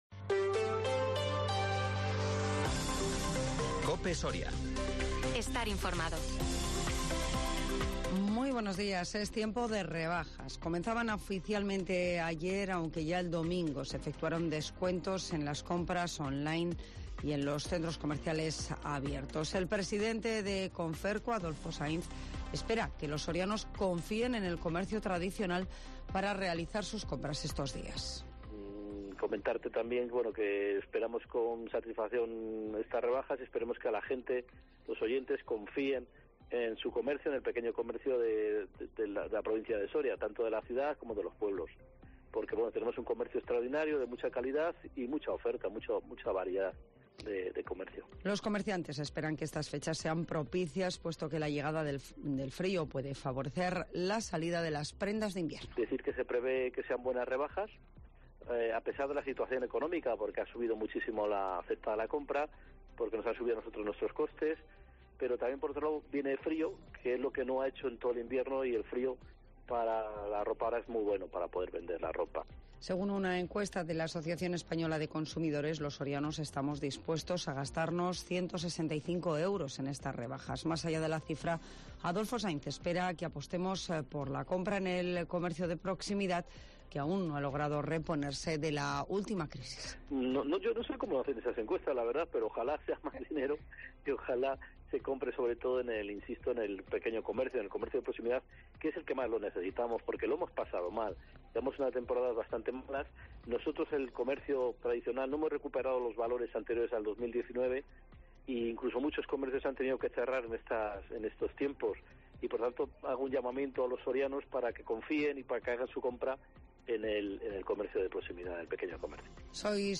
Las noticias en COPE Soria